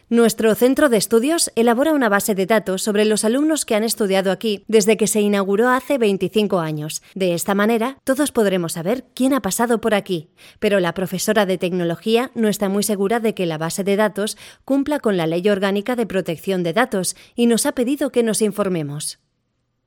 cartoon dubbing voice actress locutor